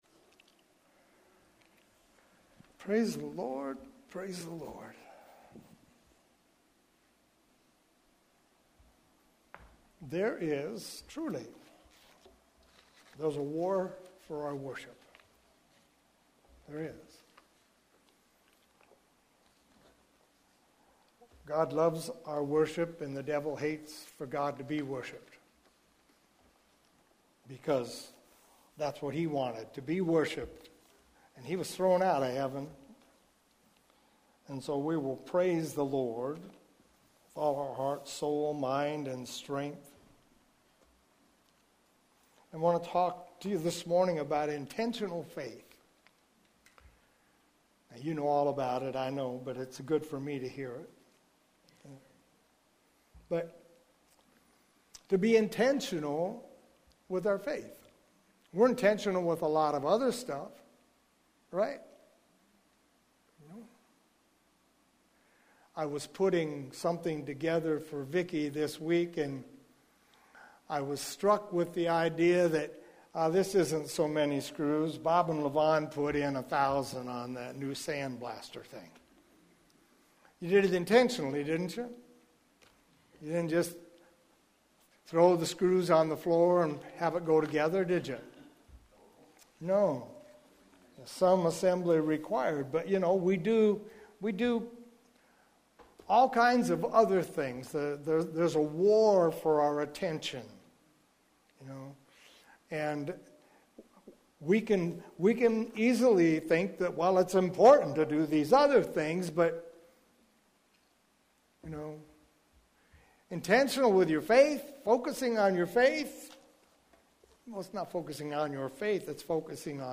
Here you’ll find a selection of audio recordings from Hosanna Restoration Church.